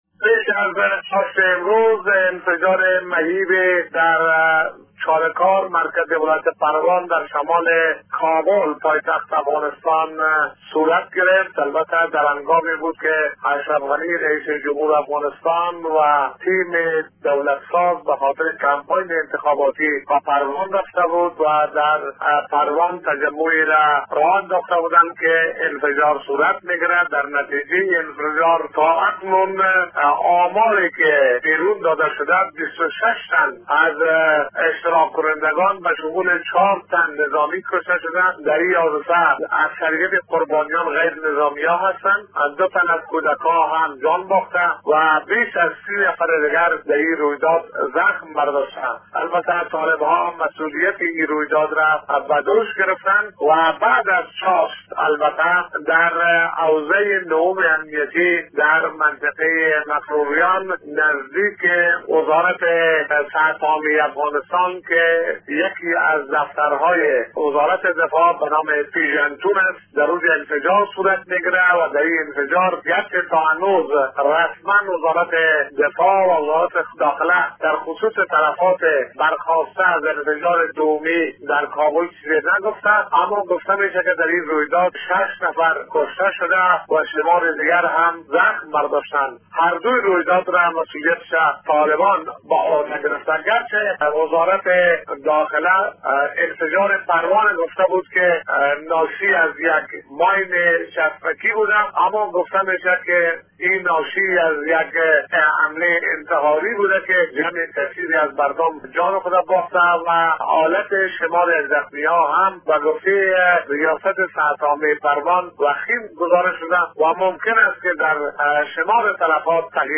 جزئیات در گزارش خبرنگار رادیودری: